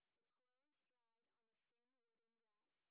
sp15_white_snr30.wav